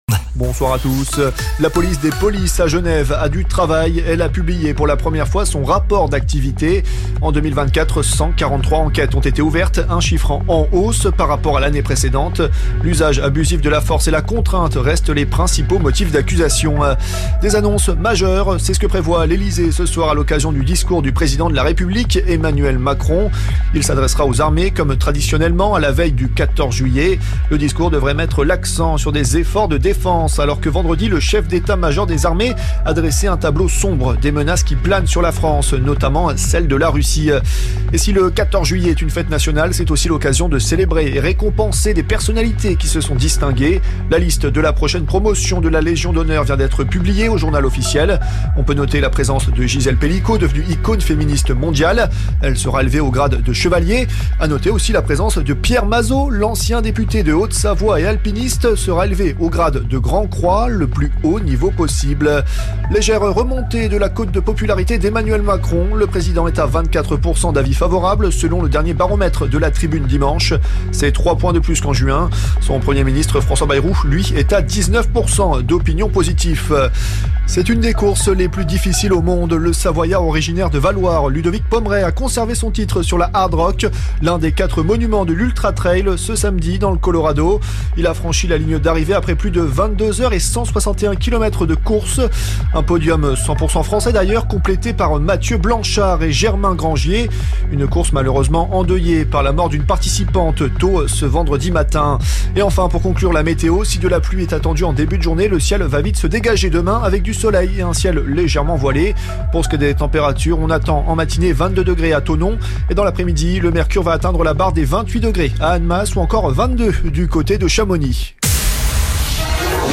Votre flash info - votre journal d'information sur La Radio Plus